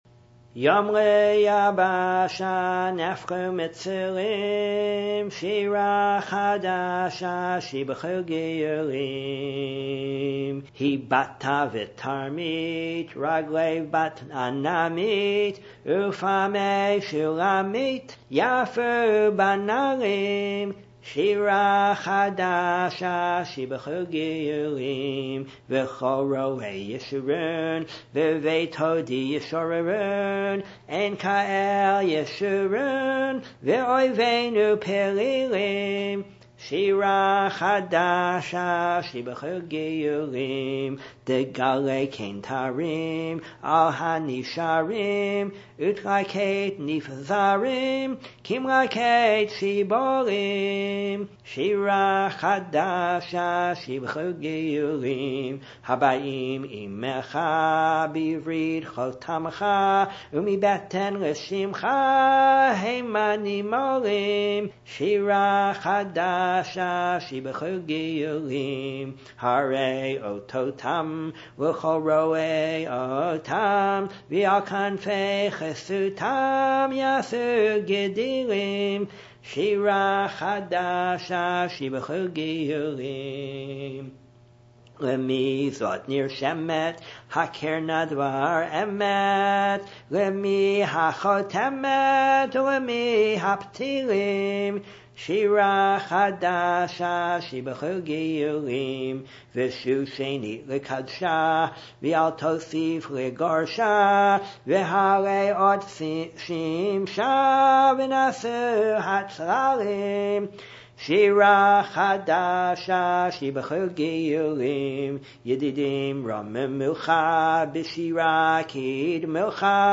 Many years ago, I learned a simple repetitive tune for this, which you can listen to here:
piyyut-yom-layabasha-by-judah-halevi-1.mp3